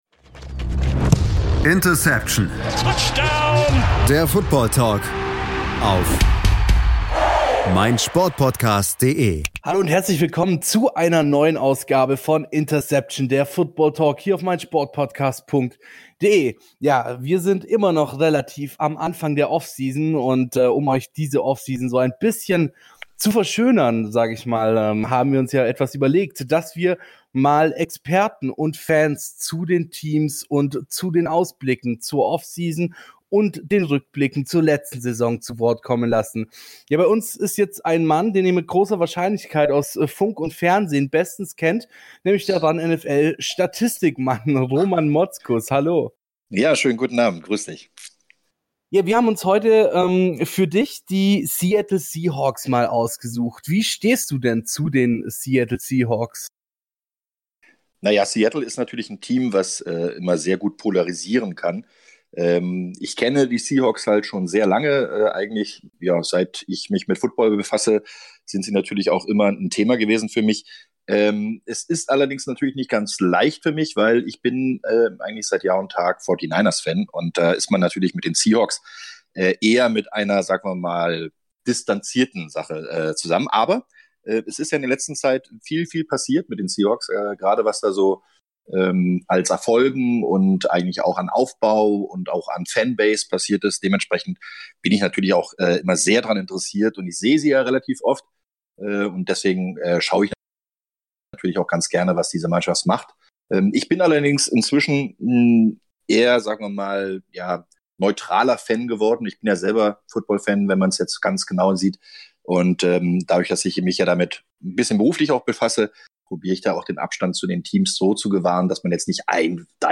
Es gibt eine Ausgabe zu jedem Team in der NFL, wo unsere Crew mit Fan-Experten über die jeweiligen Teams sprechen.